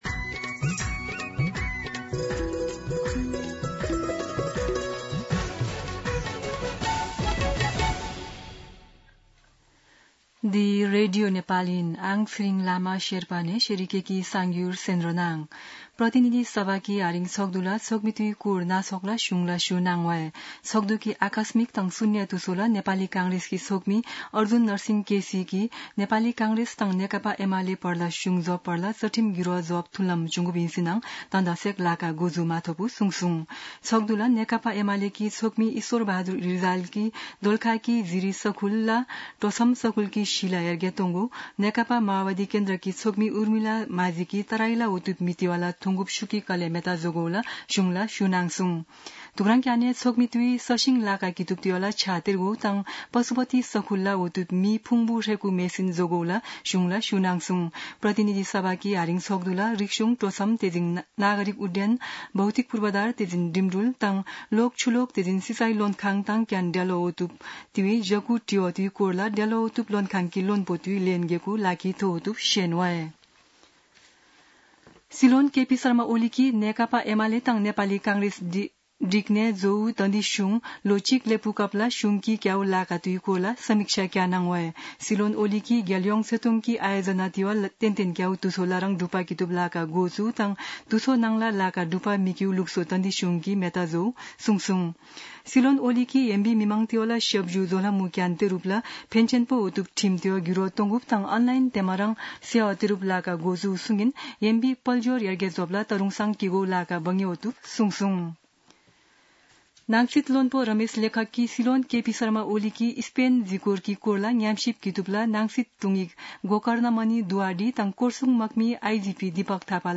शेर्पा भाषाको समाचार : ३० असार , २०८२
Sherpa-News-3-30.mp3